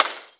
HR16B GLASS.wav